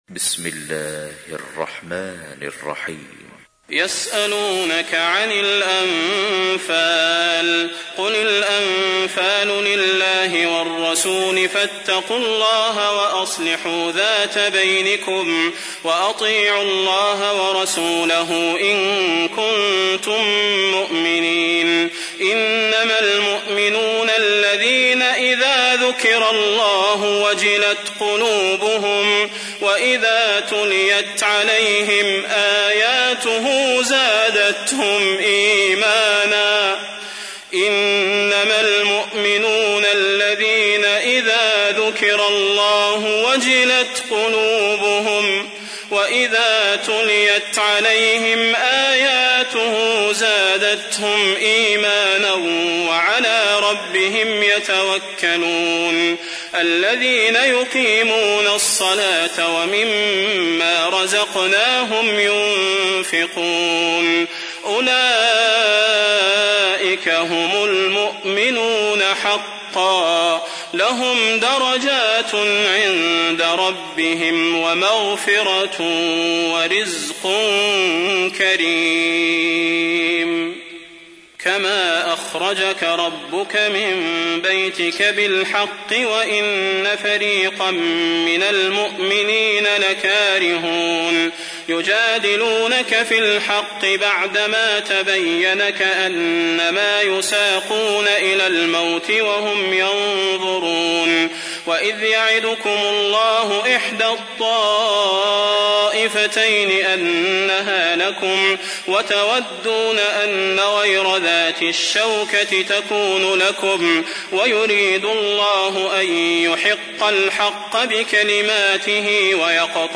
تحميل : 8. سورة الأنفال / القارئ صلاح البدير / القرآن الكريم / موقع يا حسين